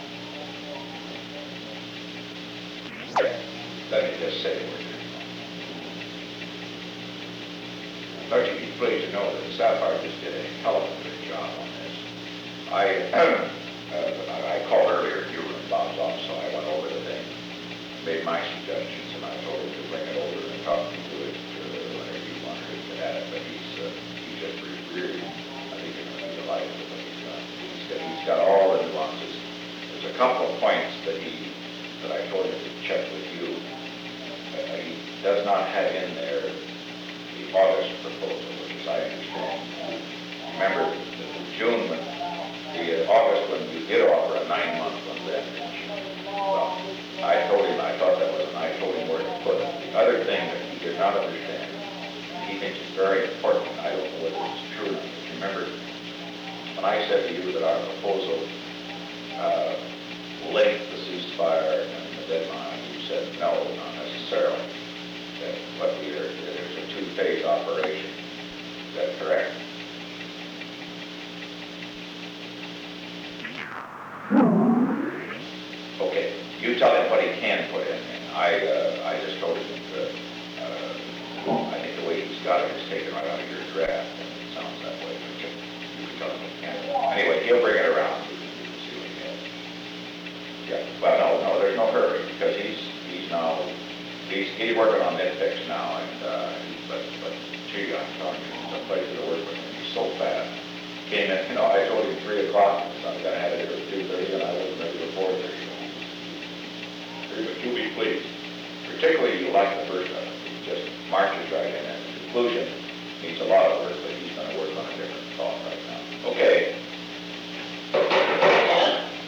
On January 13, 1972, President Richard M. Nixon and Henry A. Kissinger met in the President's office in the Old Executive Office Building at an unknown time between 5:25 pm and 5:26 pm. The Old Executive Office Building taping system captured this recording, which is known as Conversation 314-016 of the White House Tapes.